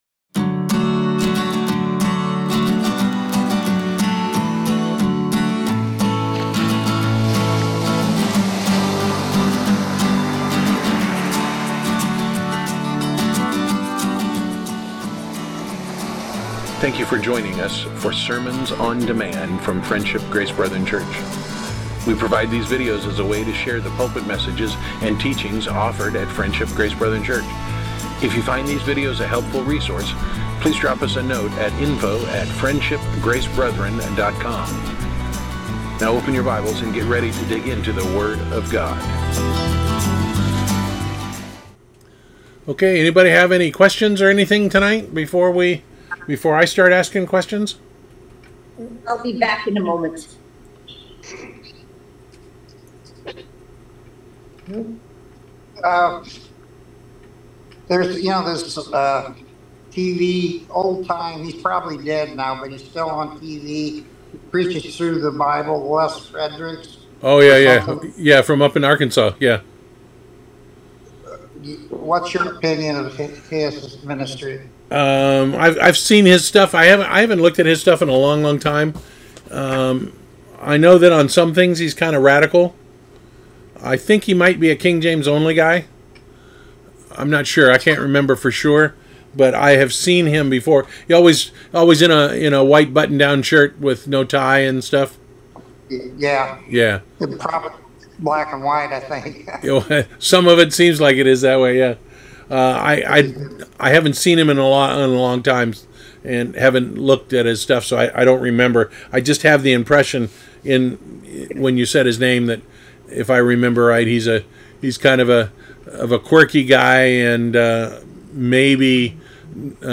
Series: Weekly Bible Discussion